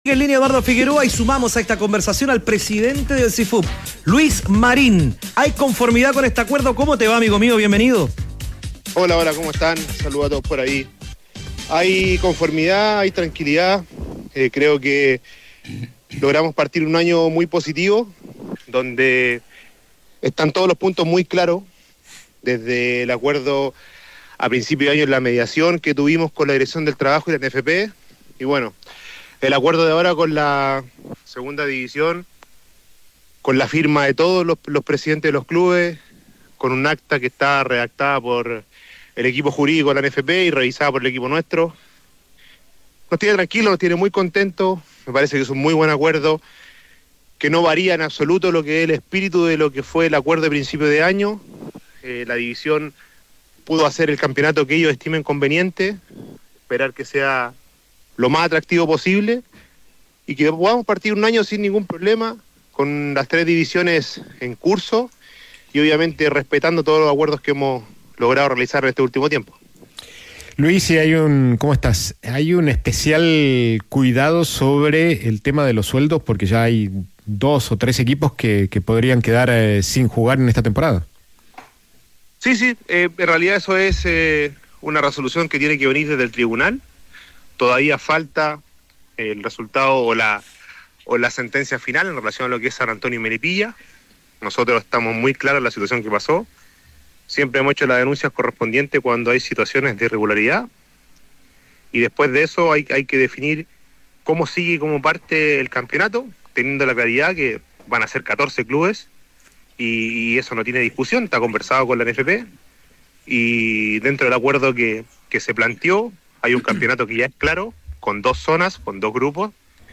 En conversación con Los Tenores de la Tarde